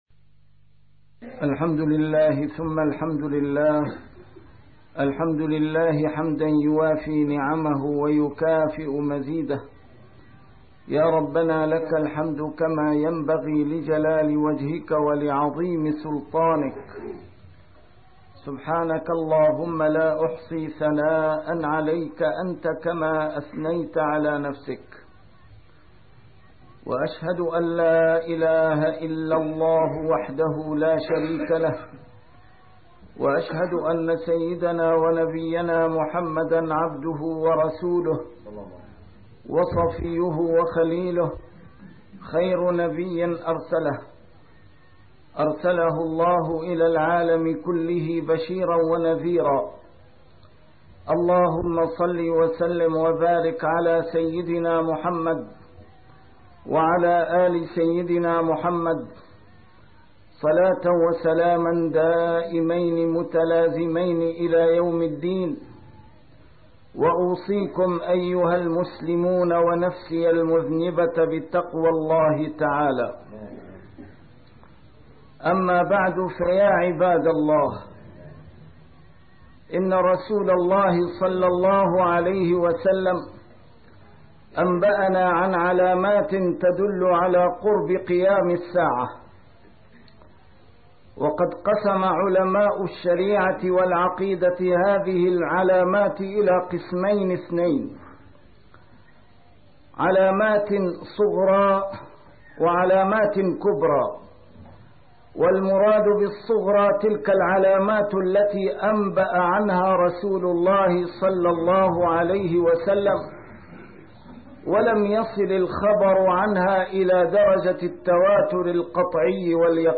A MARTYR SCHOLAR: IMAM MUHAMMAD SAEED RAMADAN AL-BOUTI - الخطب - الله الله في أصحابي